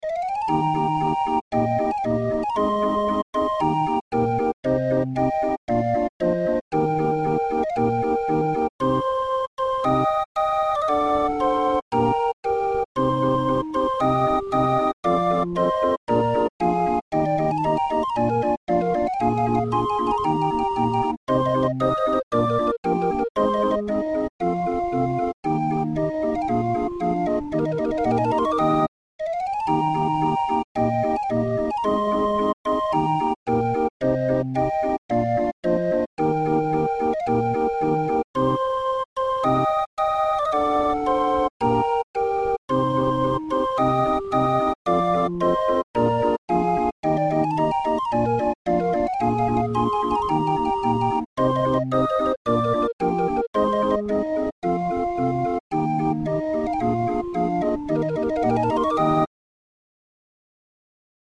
Muziekrol voor Raffin 31-er